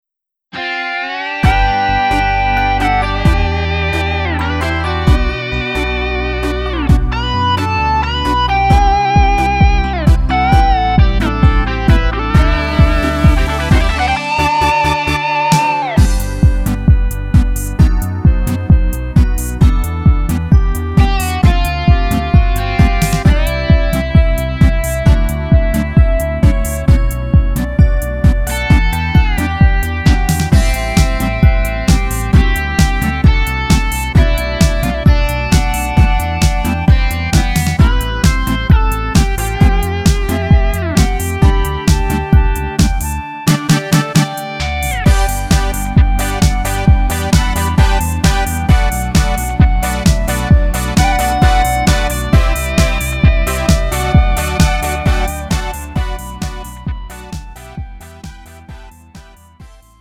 음정 원키 2:34
장르 구분 Lite MR